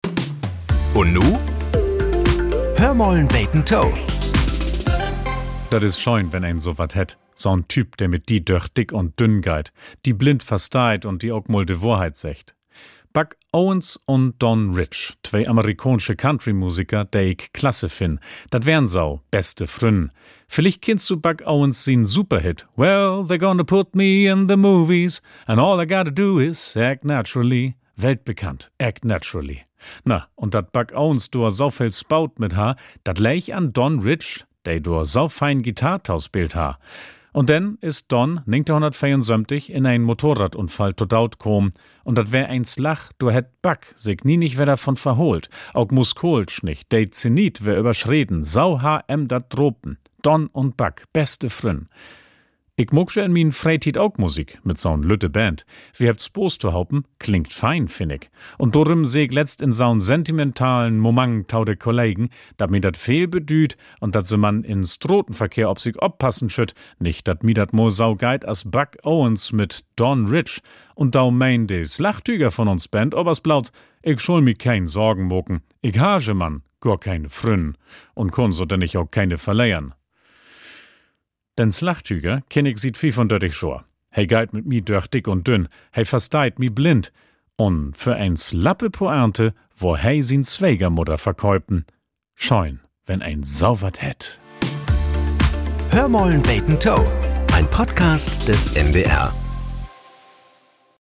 Nachrichten - 18.05.2025